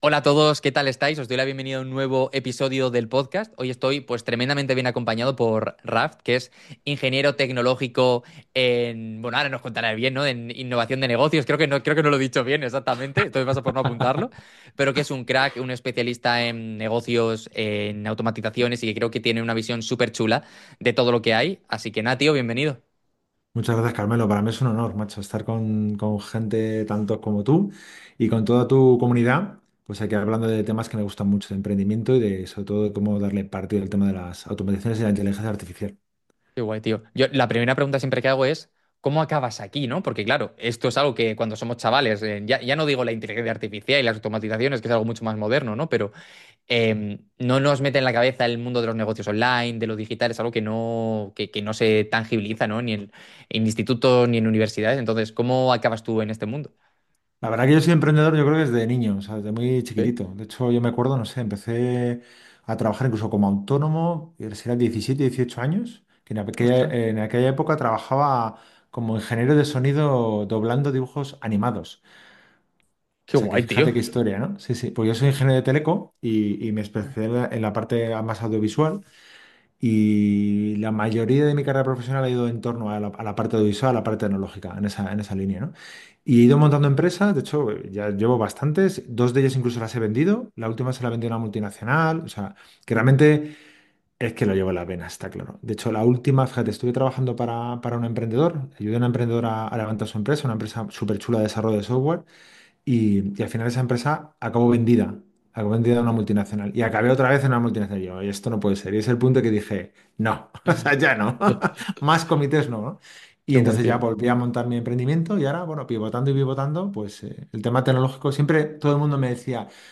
Entrevista a un Experto de Automatizaciones e IA